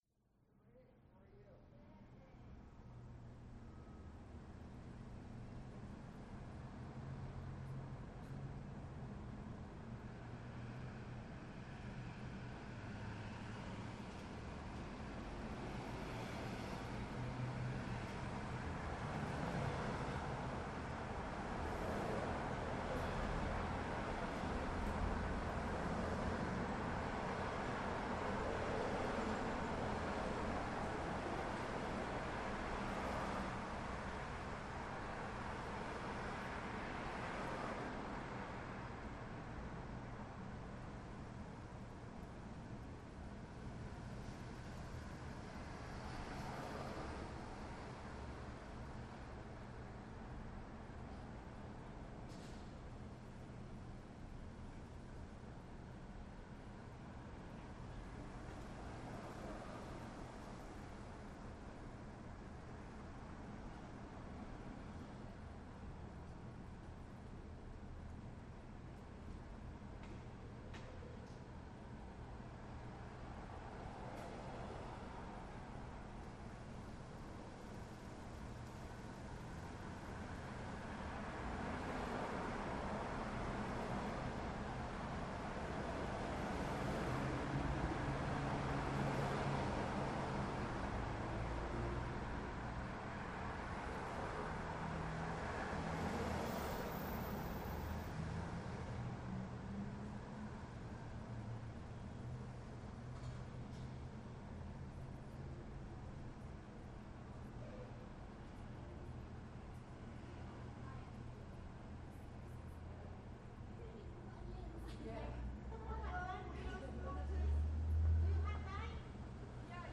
City street
There’s not much people noise, but when the light turns green at the crest of the hill before our block, a peloton of cars whooshes by, intermingled with the occasional squeal of a cab’s brakes. There’s also the twice weekly garbage collection that sounds like a wrecking crew and the hospital deliveries next door that block off a lane of traffic, creating a chain reaction of sudden lane changes and honking cars.
city-street.mp3